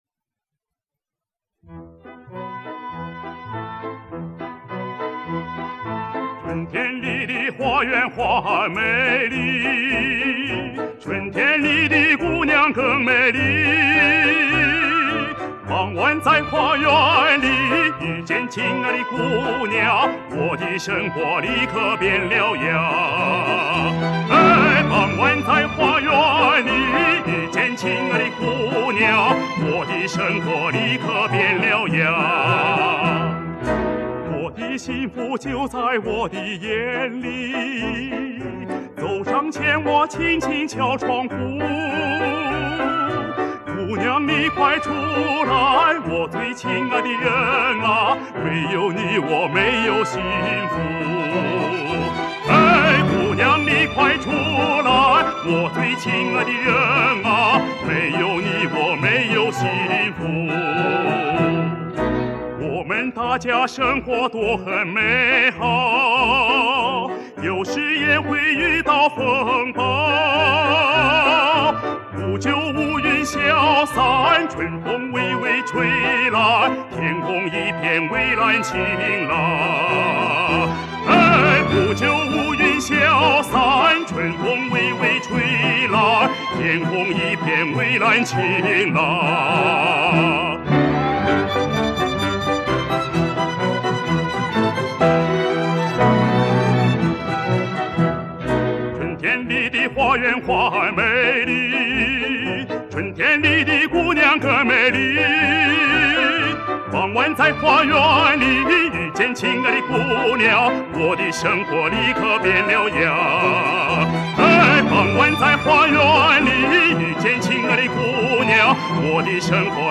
苏联歌曲
这首歌曲基本上接近男高音的音色了